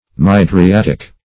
Mydriatic \Myd`ri*at"ic\, a.